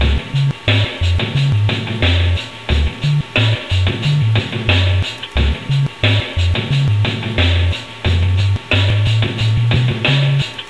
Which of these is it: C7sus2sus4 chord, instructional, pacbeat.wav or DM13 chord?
pacbeat.wav